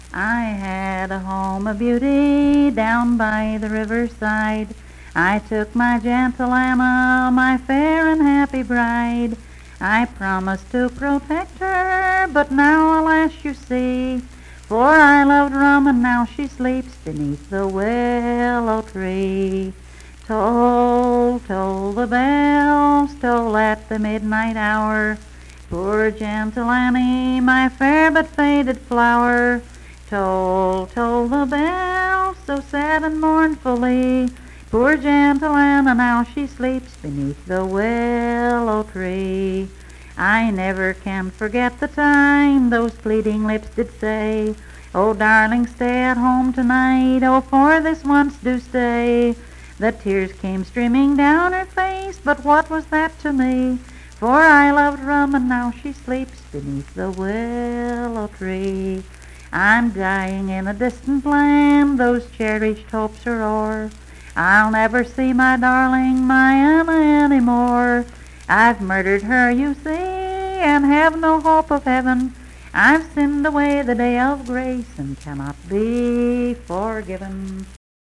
Unaccompanied vocal music performance
Verse-refrain 4d(4).
Voice (sung)